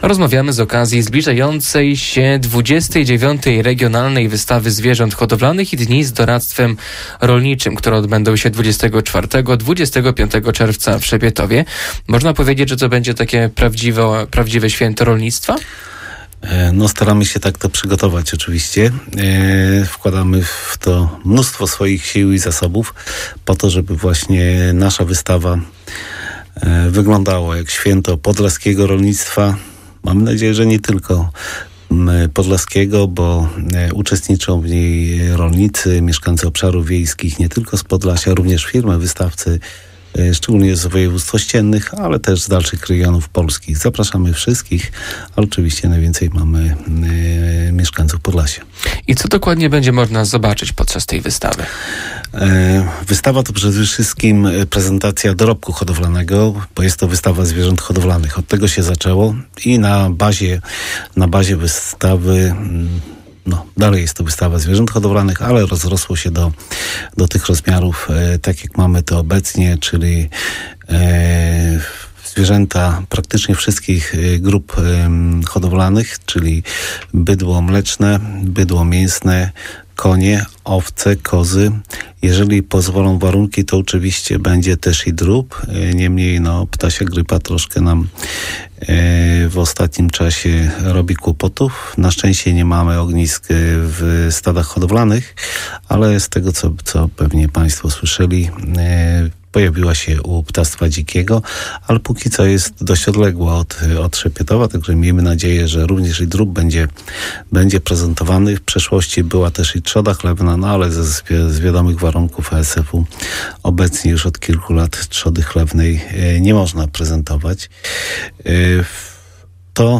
Rozmowa RN